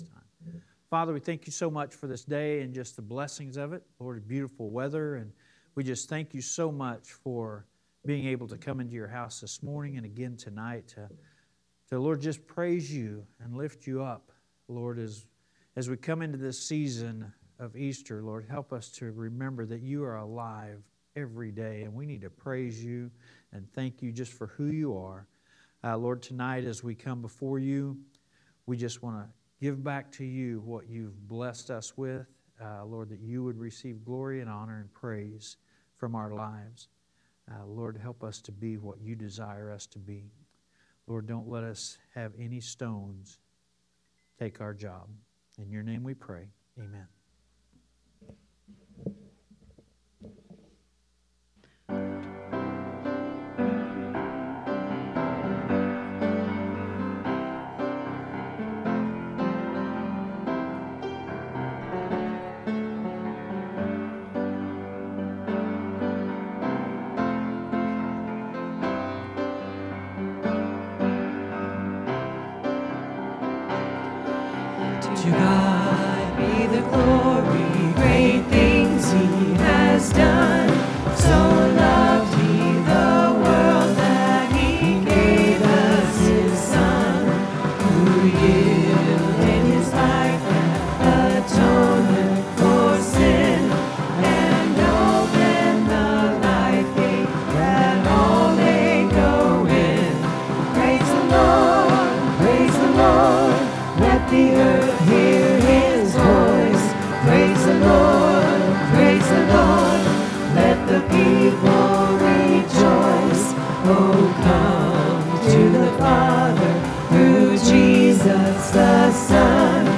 5th Sunday Sing